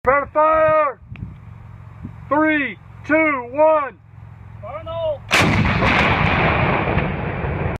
German 88mm Live fire